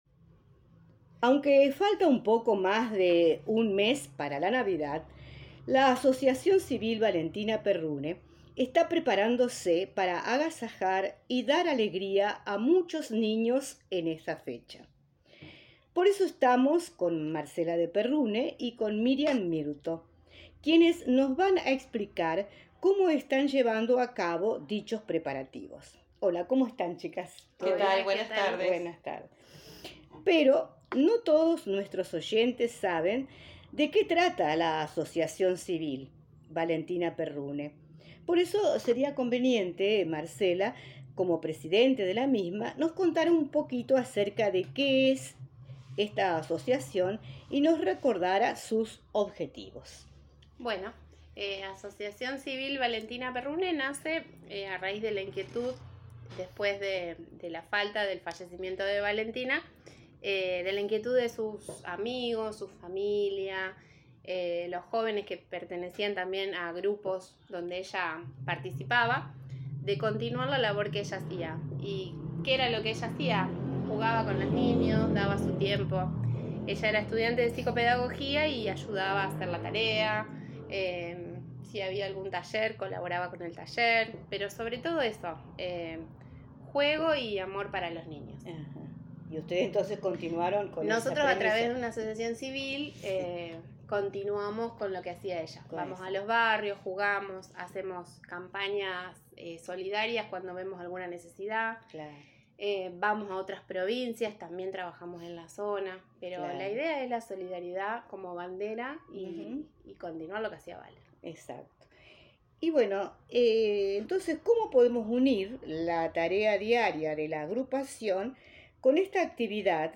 Así lo explicaron en una reciente entrevista en el bloque "Honrar la Vida" de Radio Nueva Estrella